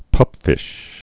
(pŭpfĭsh)